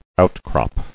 [out·crop]